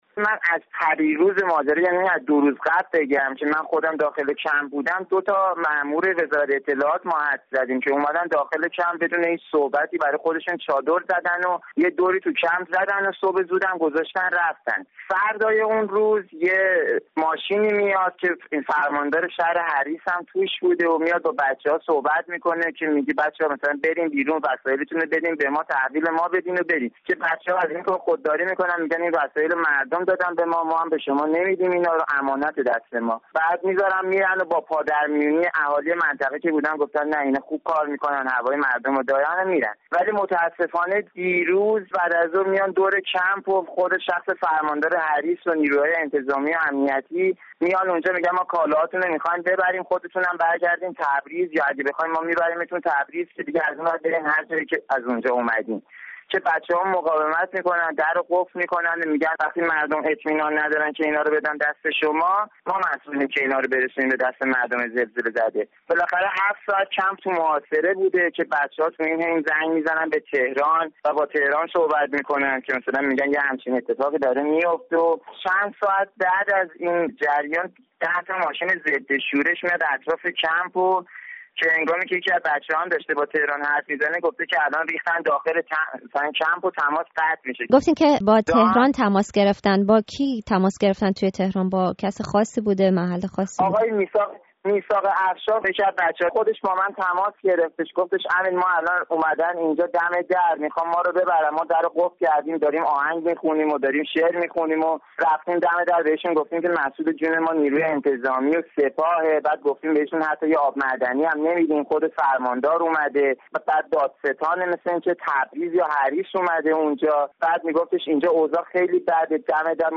گفت‌و‌گوی